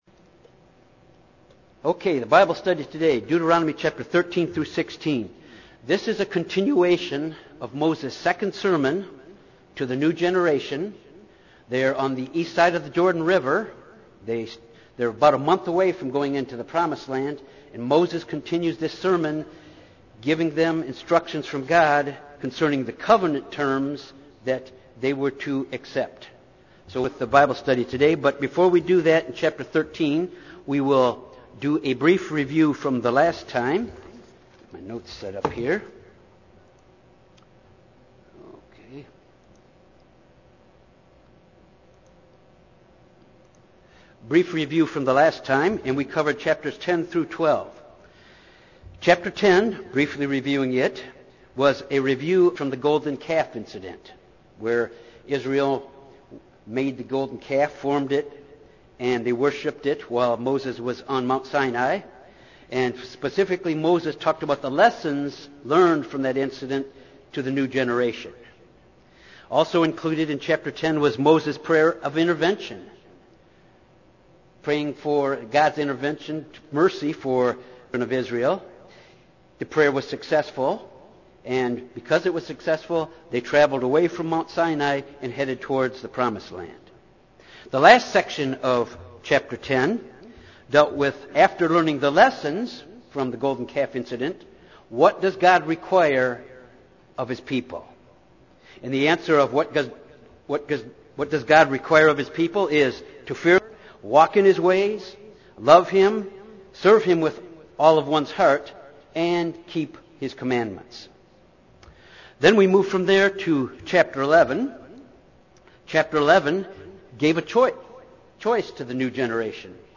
This Bible study covers Deuteronomy 13-16 which is a continuation of Moses’ second sermon to the new generation of the Children of Israel just before they enter into the Promised Land. The laws of clean and unclean animals are covered which are based upon a holiness principle.